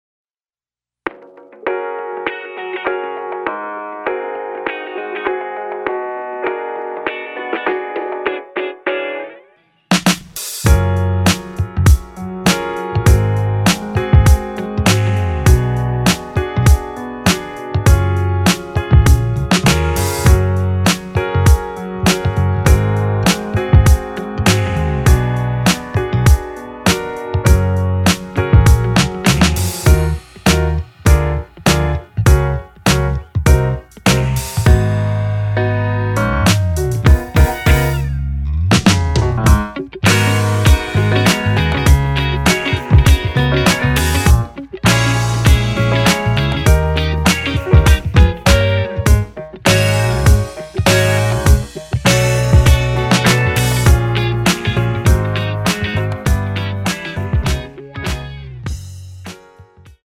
Gb
앞부분30초, 뒷부분30초씩 편집해서 올려 드리고 있습니다.
공식 MR